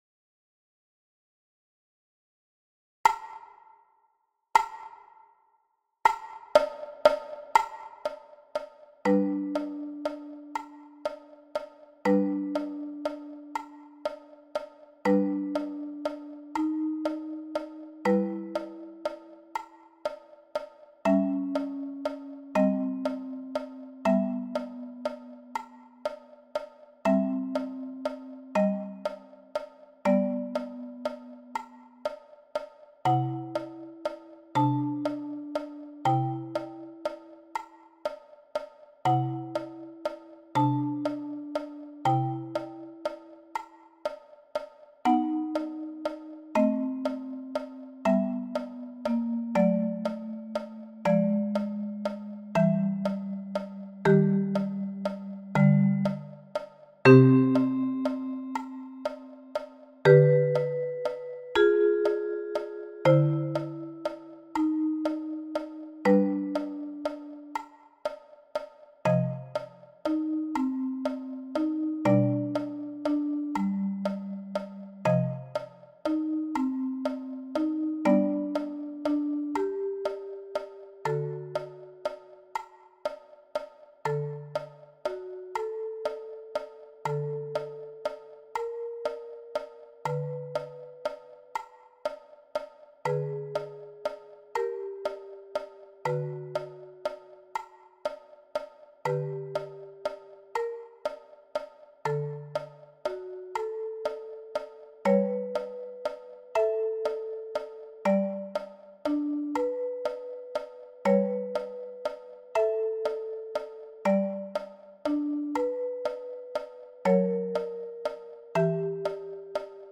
Sheherazade – prince et princesse – accompagnement – 40 bpm